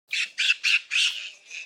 دانلود صدای میمون 8 از ساعد نیوز با لینک مستقیم و کیفیت بالا
جلوه های صوتی